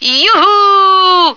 F1yeehaw.ogg